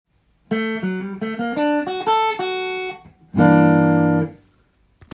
マイナー系フレーズ①
①のフレーズは、Gm keyの曲で使え、
スケール的にはGのメロディックマイナースケール
osyare.ending1.m4a